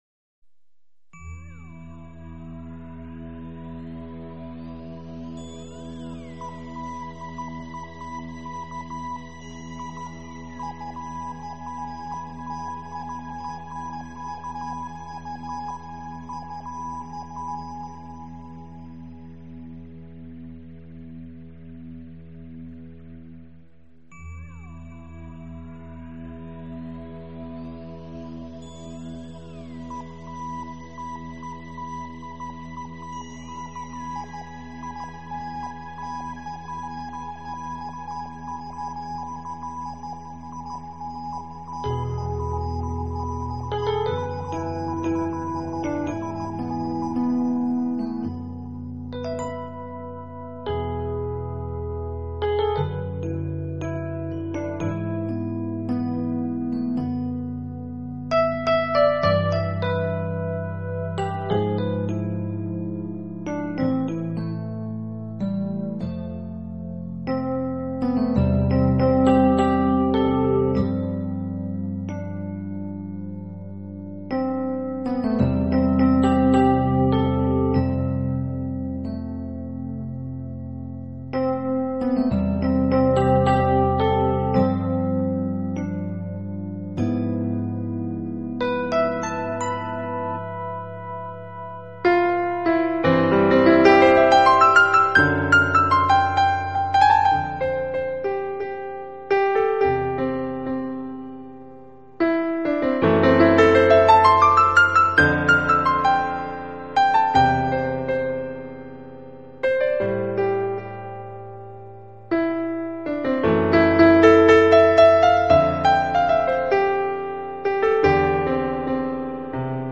【新世纪音乐】